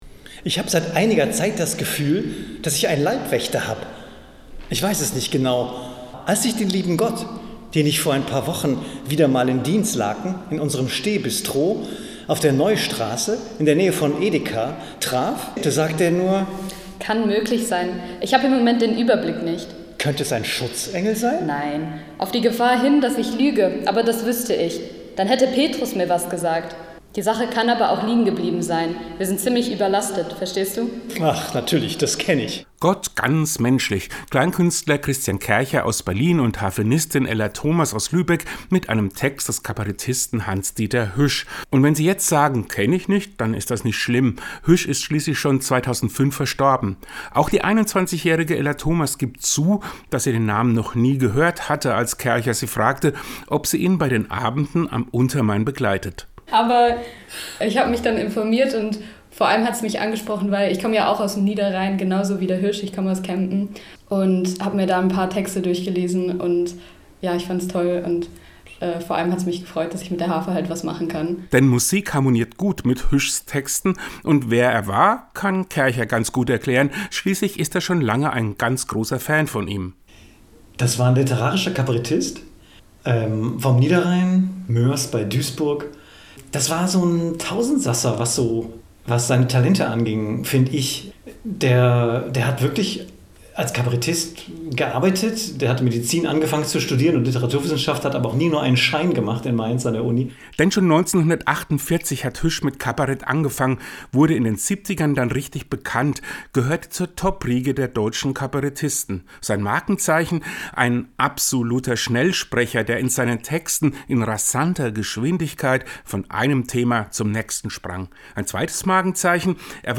Letze Woche gab es zwei ganz besondere Aufführungen in Miltenberg und Aschaffenburg
rezitierte Texte von Hanns Dieter Hüsch
Harfe